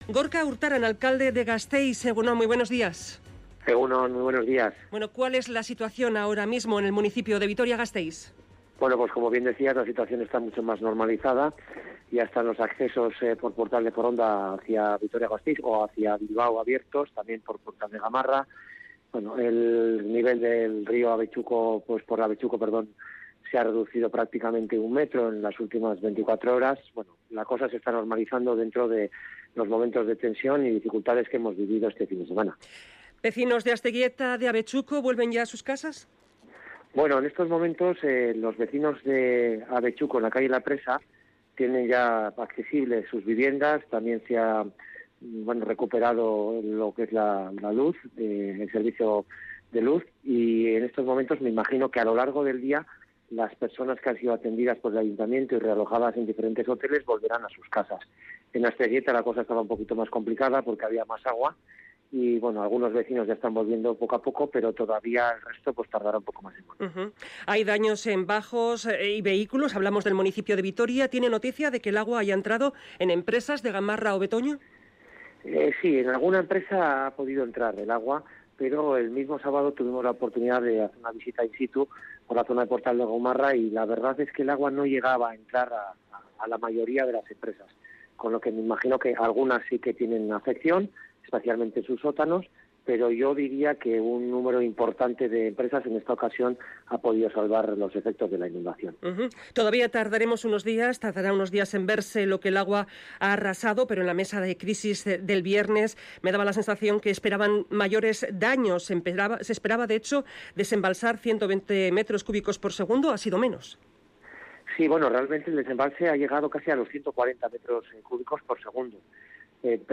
Entrevistado en Radio Vitoria, el alcalde de Vitoria-Gasteiz, Gorka Urtaran, asegura que las instituciones y URA tienen que abordar ya las dos fases que quedan pendientes para evitar inundaciones